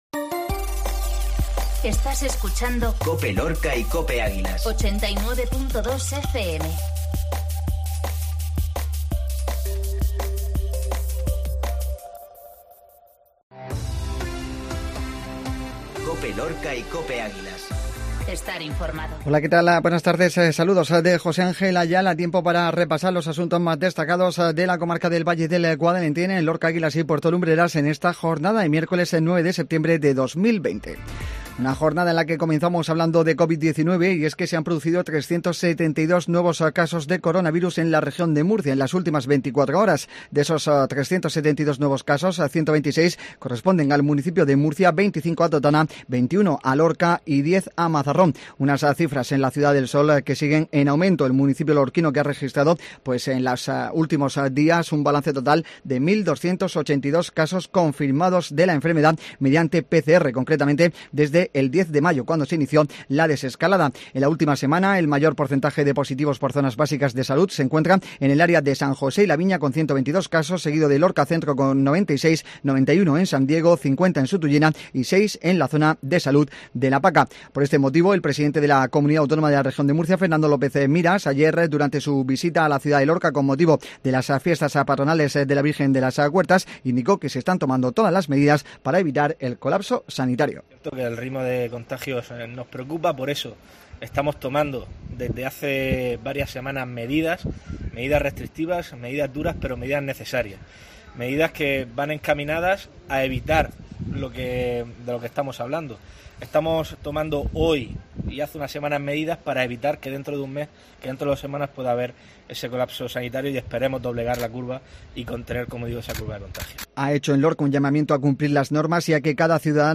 INFORMATIVO MEDIODÍA COPE LORCA 0909